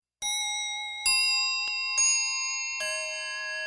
• Качество: 129, Stereo